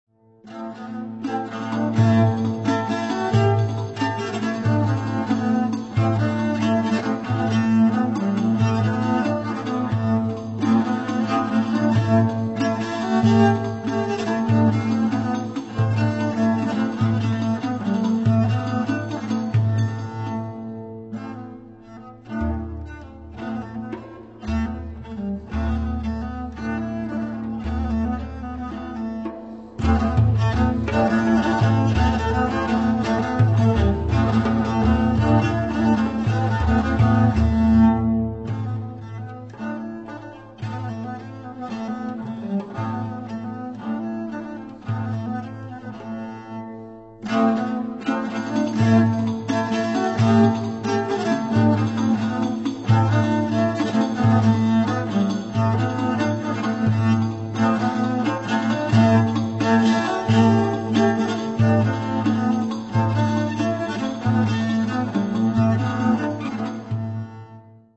LA VIELLE MEDIEVALE